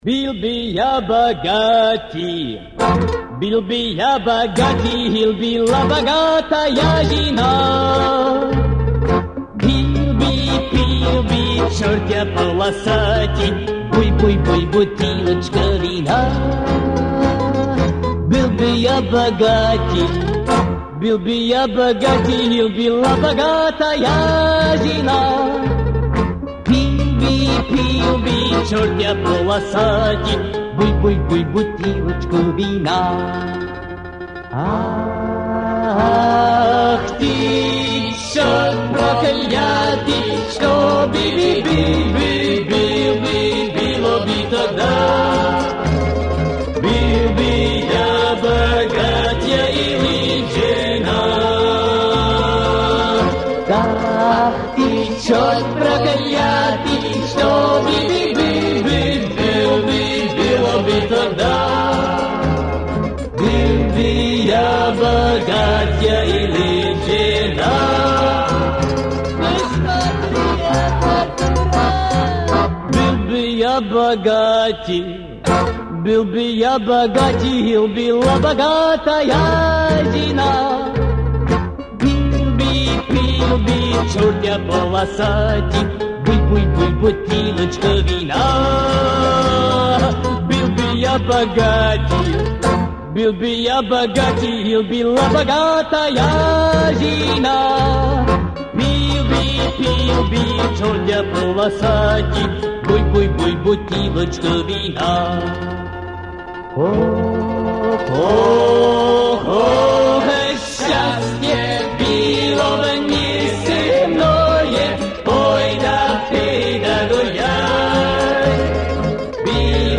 которую в пародийном ключе спела группа из Франкфурта